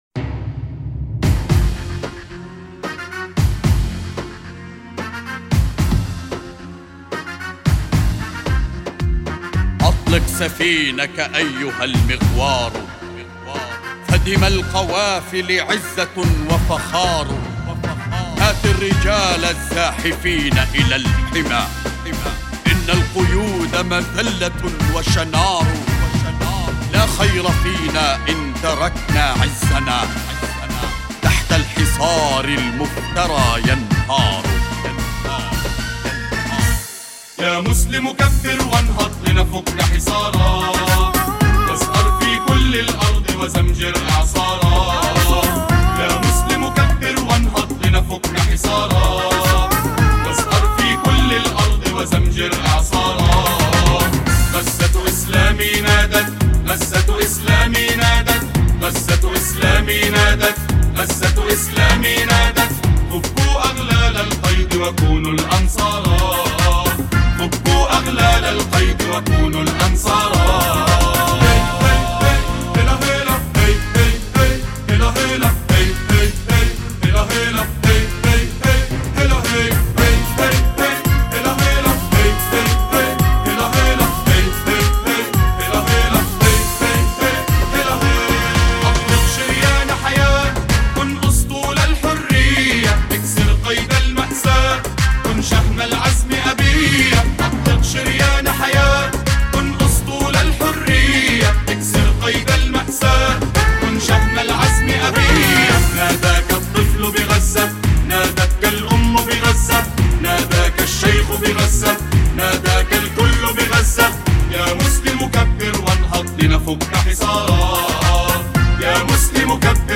أناشيد فلسطينية نشيد